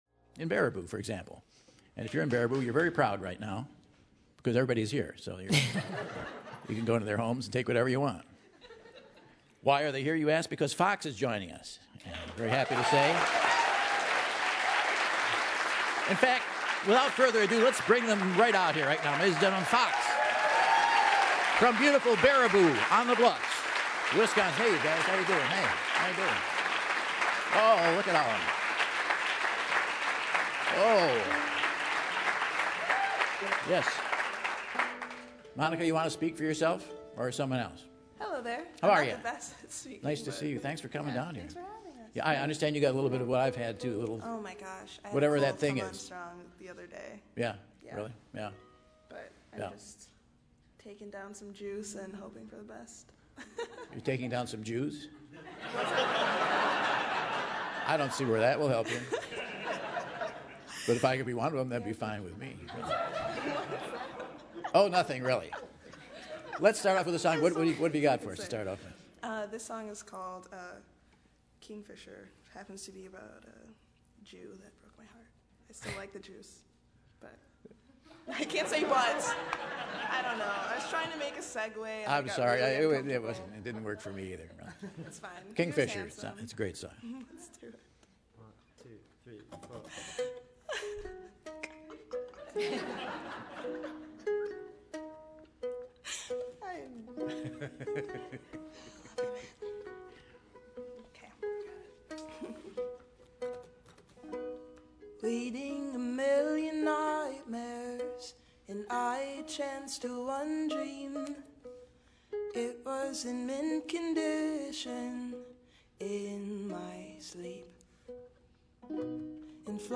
They make music that straddles Feist and Monty Python.